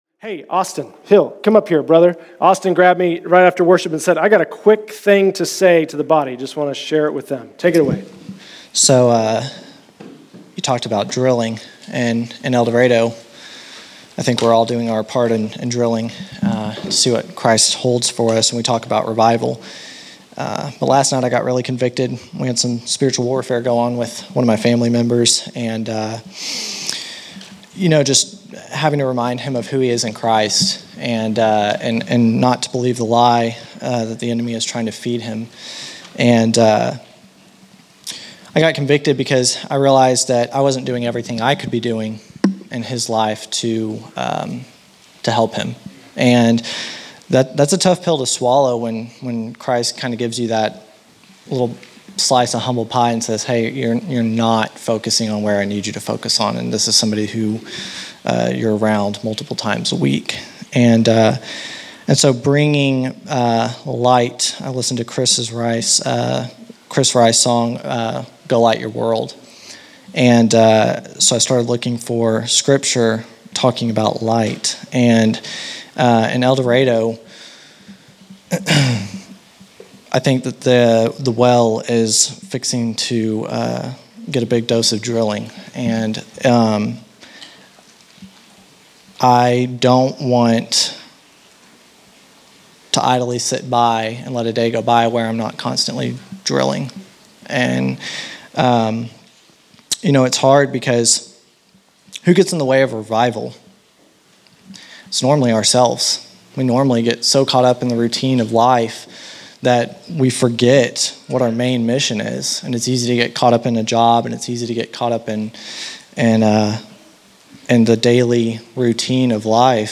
Family Testimony